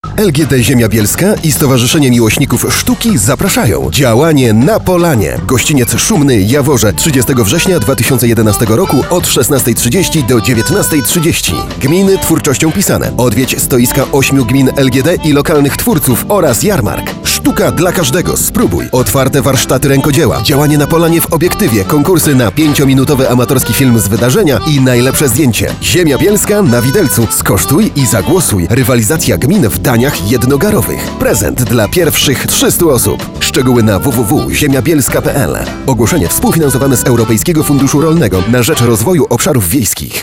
Do pobrania Plakat Spot radiowy, który od 23 wrze�nia b�dzie emitowany w Radiu Bielsko Mapy dojazdu